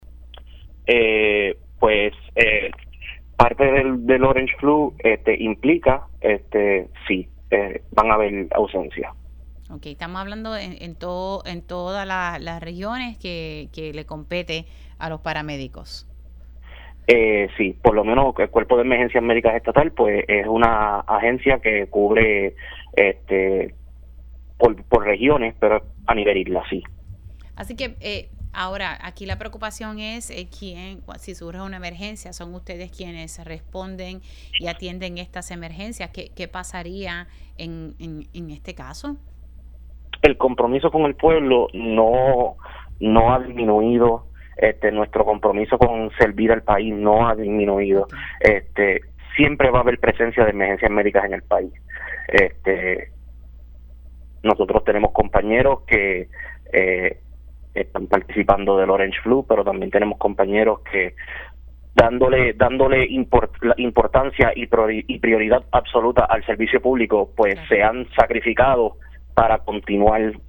510-PARAMEDICO-HABRAN-AUSENCIAS-ESTE-FIN-DE-SEMANA-EN-CUERPO-EMERGENCIAS-ESTATAL.mp3